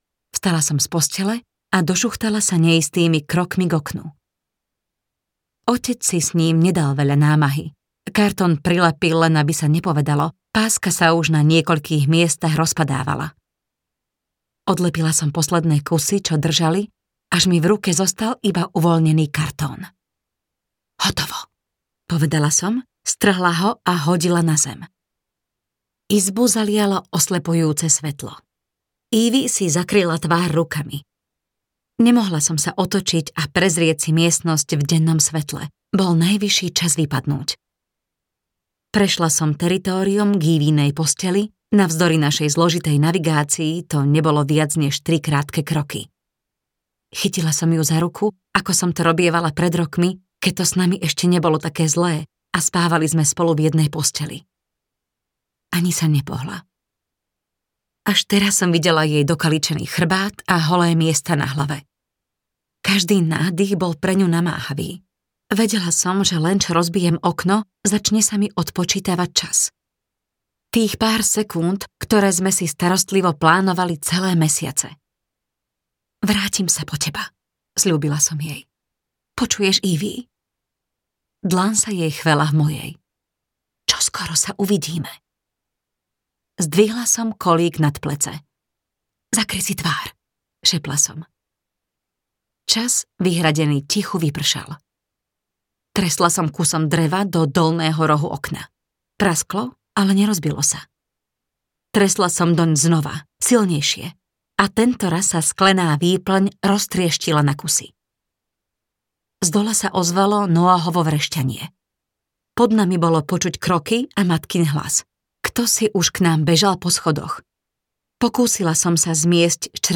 Dievča A audiokniha
Ukázka z knihy